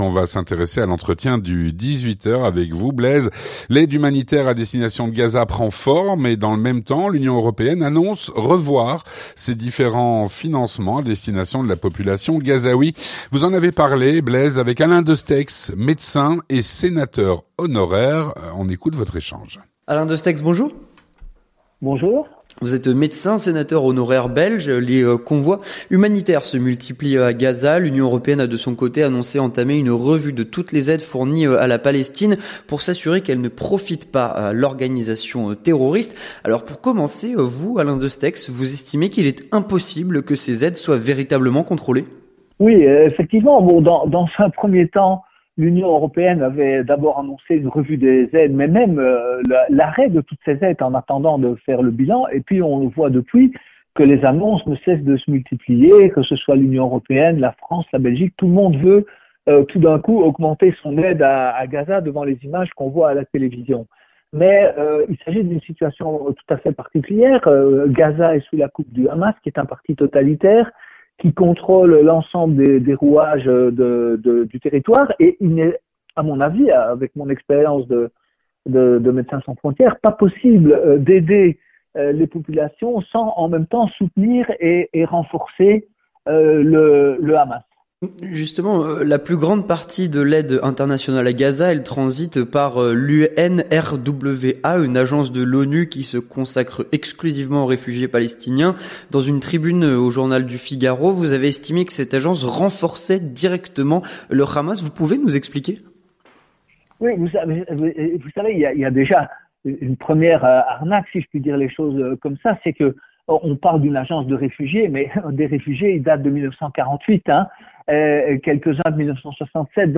L'entretien du 18H - L’aide humanitaire à destination de Gaza. Avec Alain Destexhe (31/10/2023)
Avec Alain Destexhe, médecin et sénateur honoraire.